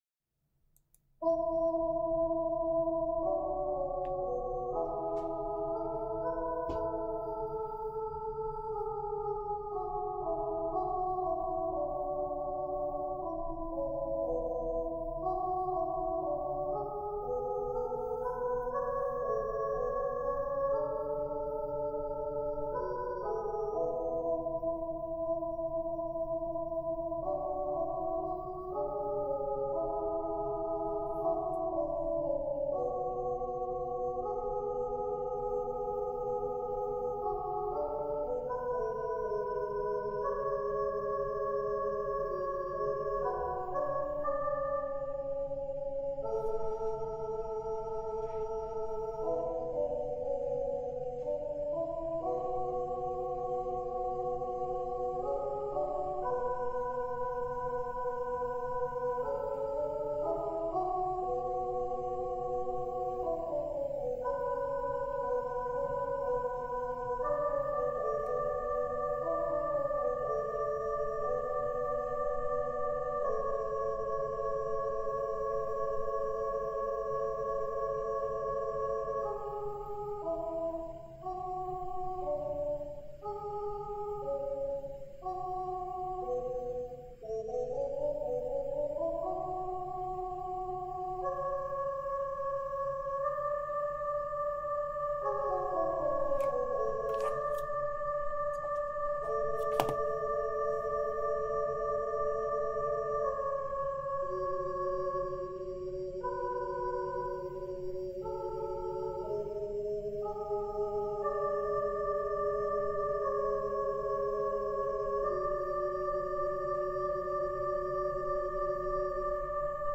\set Staff.midiInstrument = "pad 4 (choir)"
\set Staff.instrumentName = "soprano" \soprano }
\set Staff.instrumentName = "alto   " \alto }
\set Staff.midiInstrument = "acoustic grand"       \pianoUP }